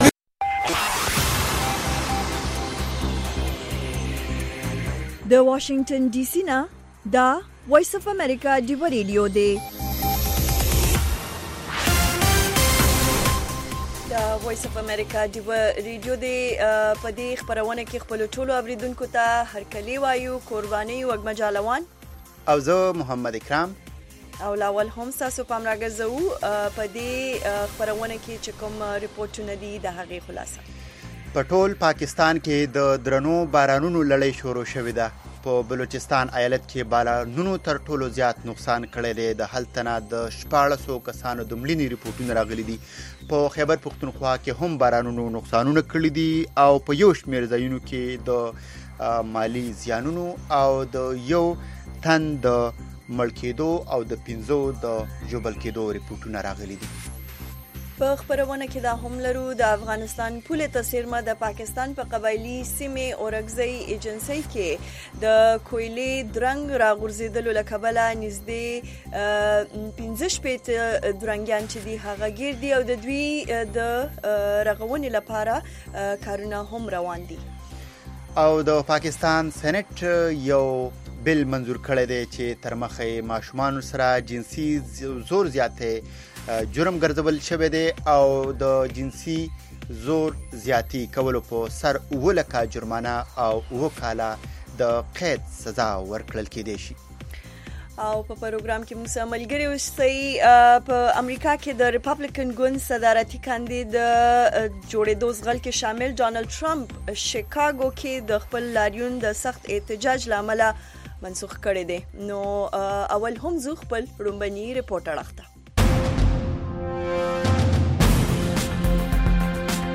د وی او اې ډيوه راډيو ماښامنۍ خبرونه چالان کړئ اؤ د ورځې د مهمو تازه خبرونو سرليکونه واورئ.